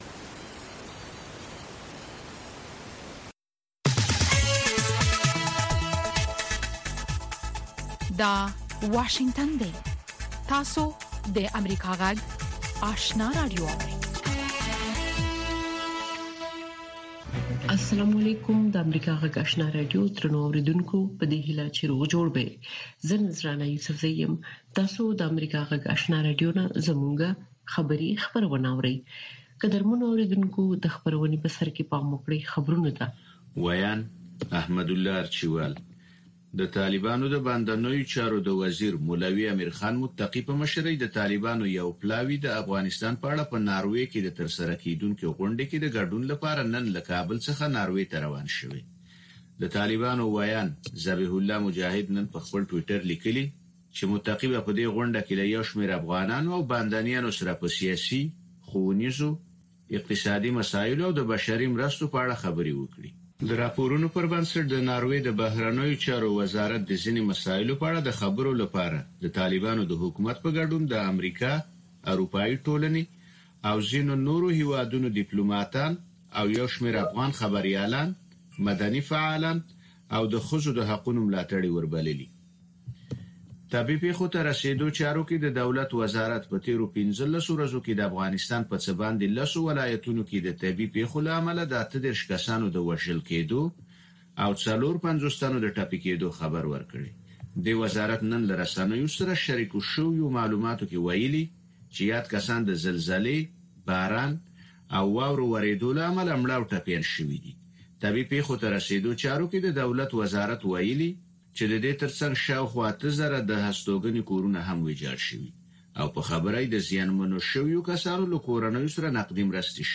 لومړنۍ ماښامنۍ خبري خپرونه